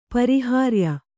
♪ pariyārya